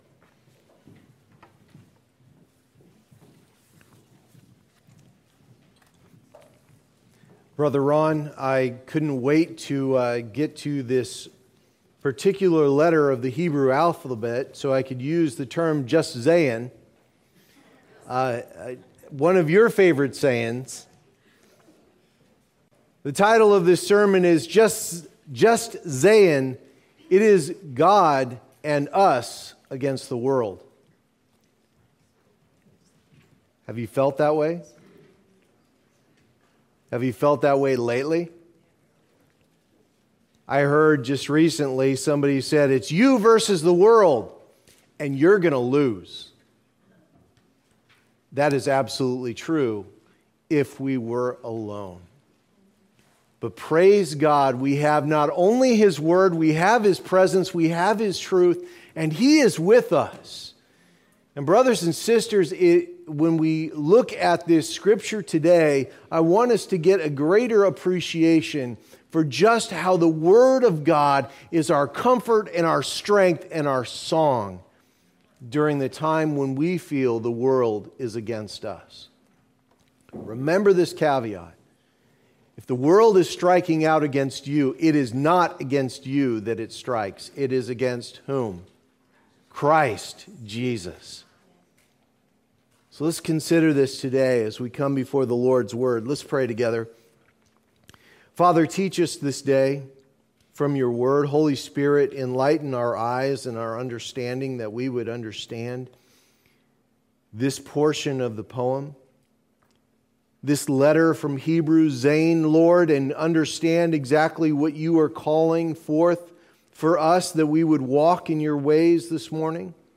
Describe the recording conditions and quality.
The ABCs of God's Word Passage: Psalm 119:49-56 Services: Sunday Morning Service Download Files Notes Previous Next